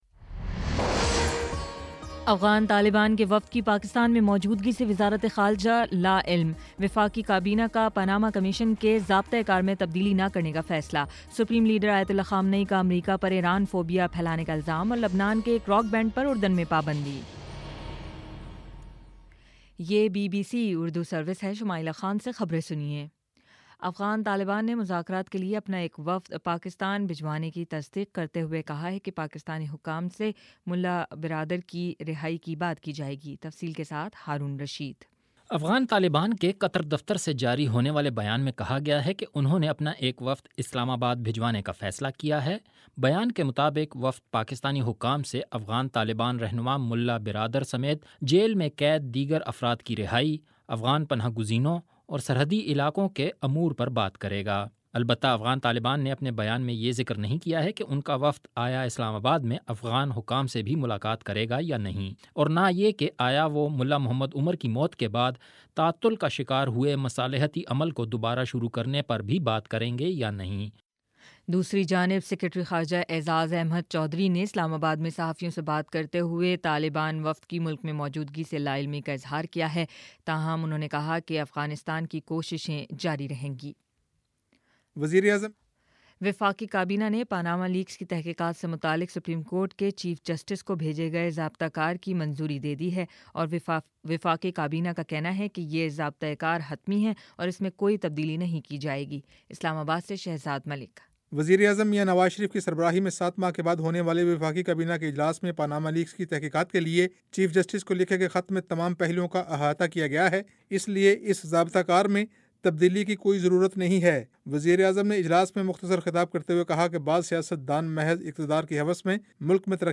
اپریل 27 : شام سات بجے کا نیوز بُلیٹن